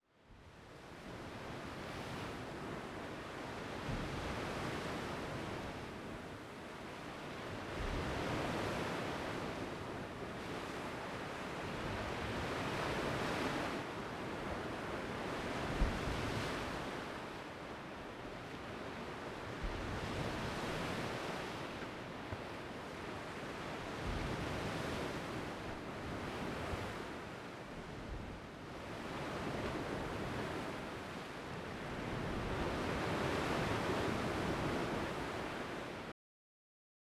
OceanWaves.wav